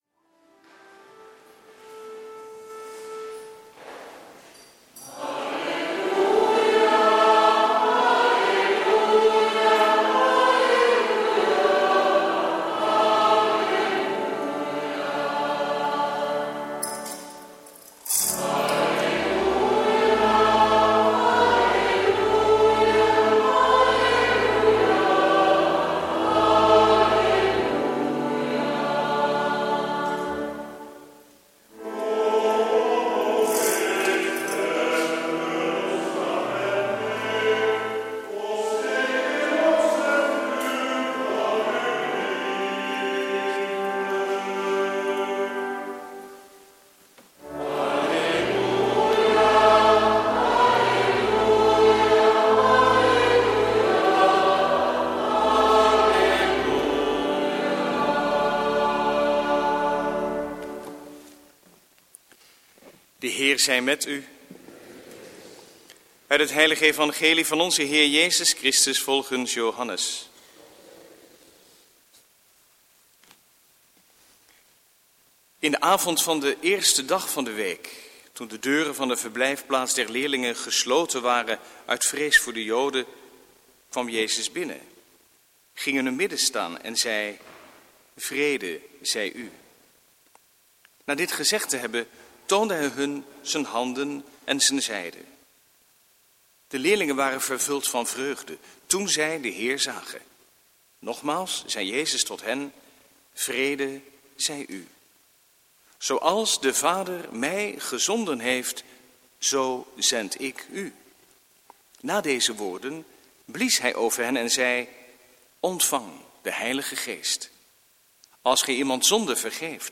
Preek Hoogfeest Pinksteren, jaar A, 7/8 juni 2014 | Hagenpreken
Lezingen